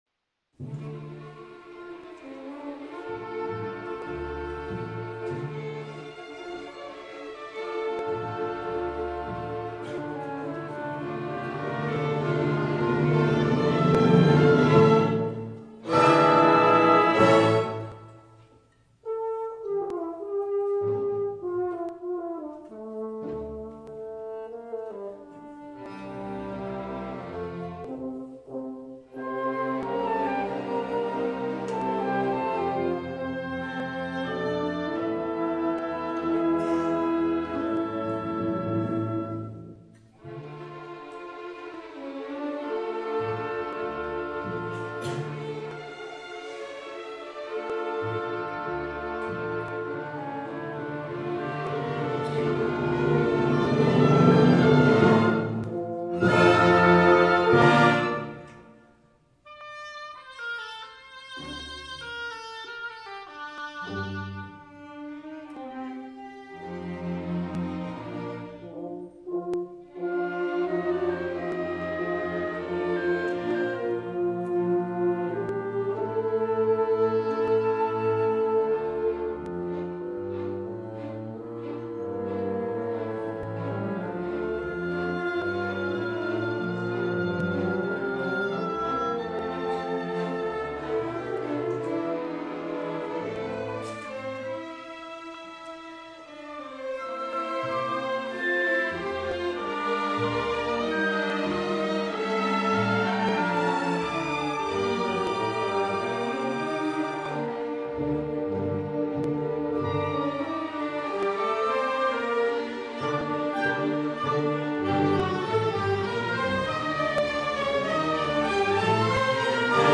Winners' Concert 2012